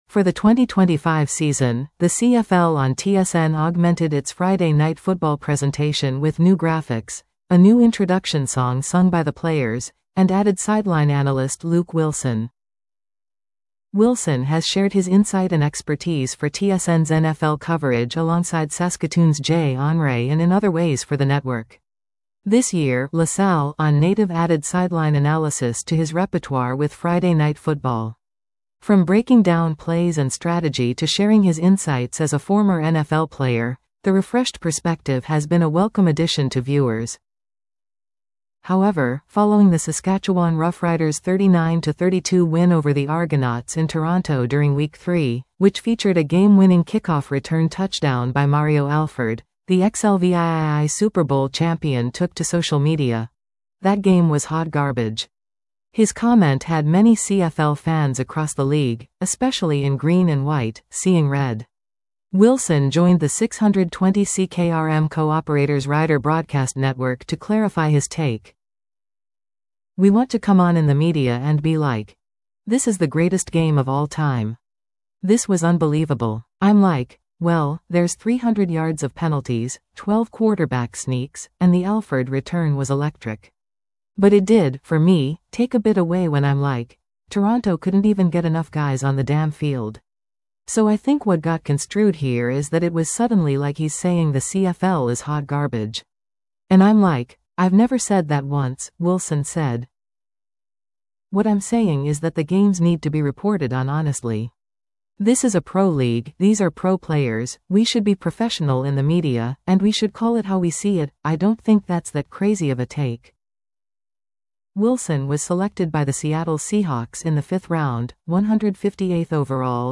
Willson joined the 620 CKRM Co-operators Rider Broadcast Network to clarify his take.